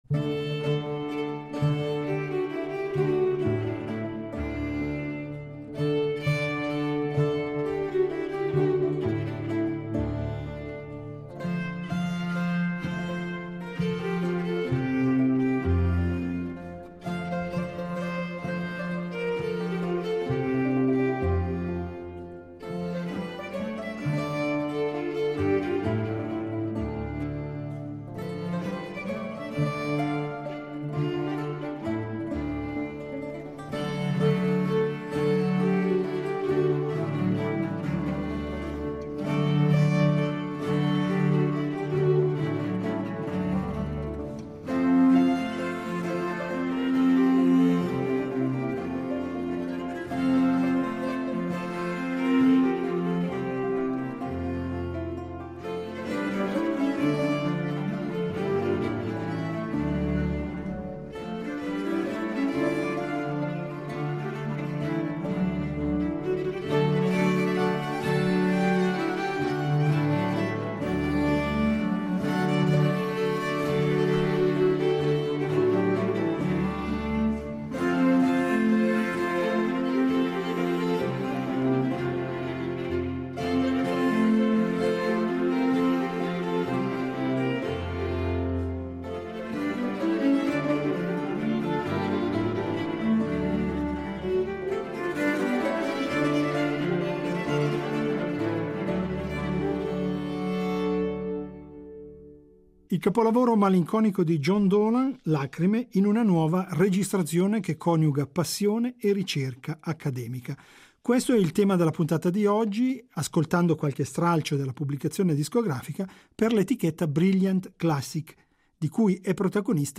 Questa straordinaria pagina è stata di recente registrata negli studi della nostra Radio.